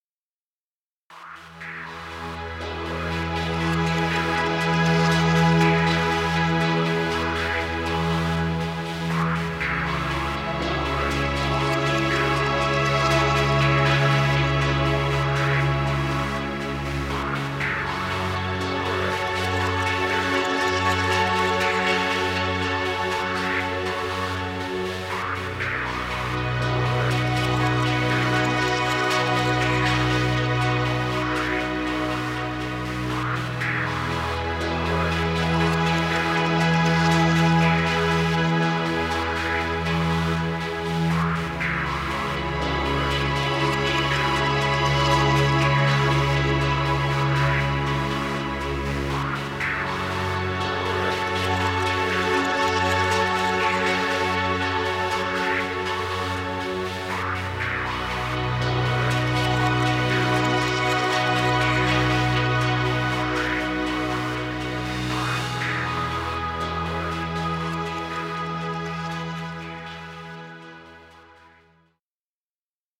Ambient music.